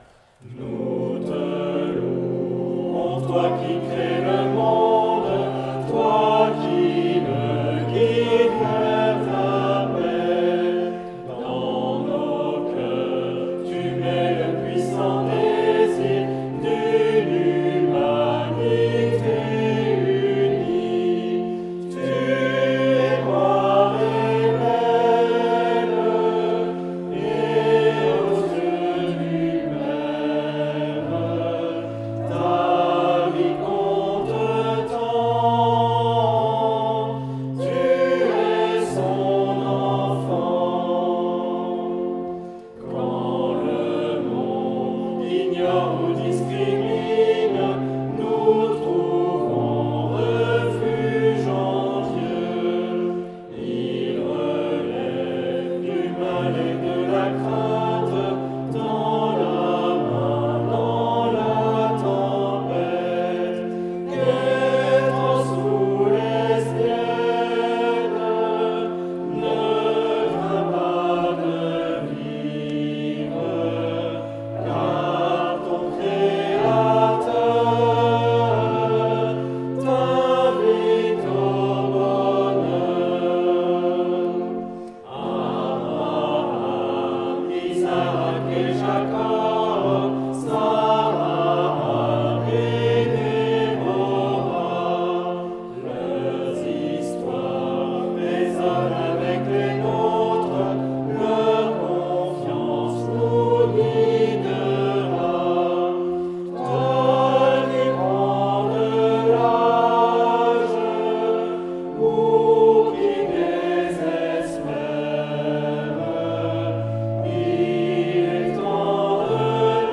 Enregistrement amateur lors d’un culte dominical au temple de Roubaix :
Cantique-Arc-en-Ciel_Roubaix_culte-live-amateur.mp3